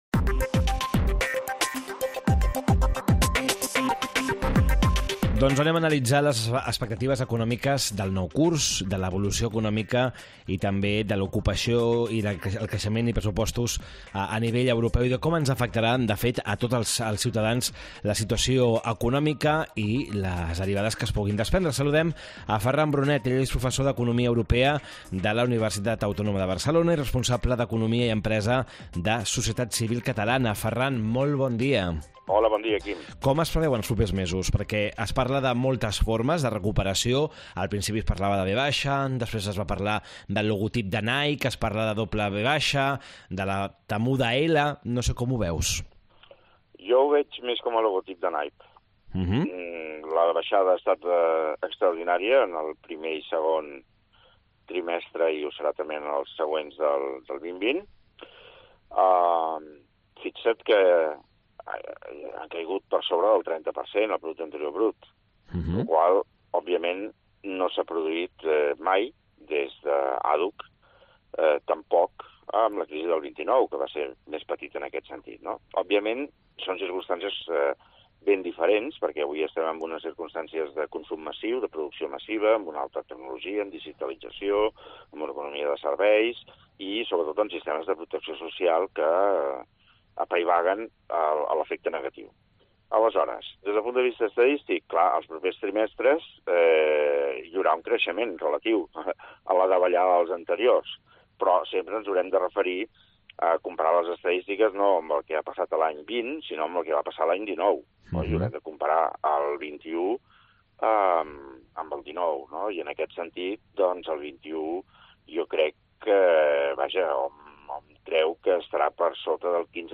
Entrevista
Duros a quatre pessetes, el programa d’economia de COPE Catalunya i Andorra.